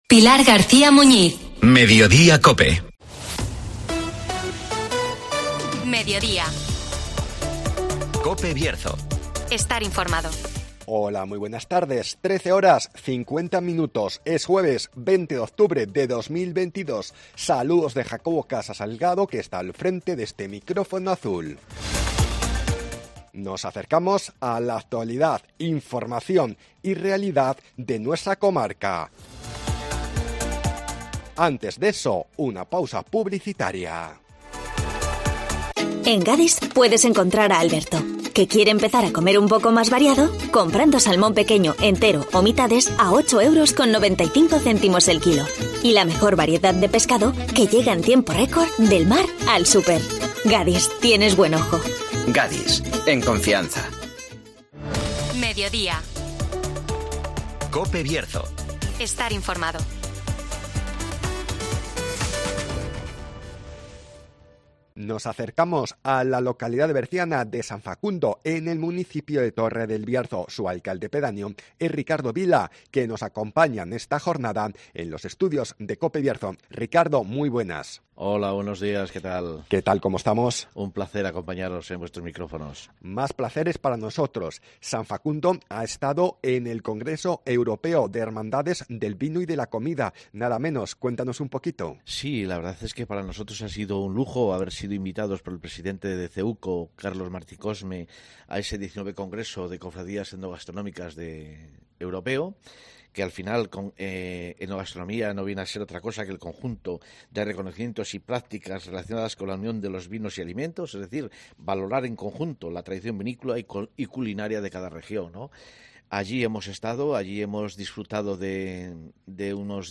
Nos acercamos a la localidad berciano de San Facundo (Entrevista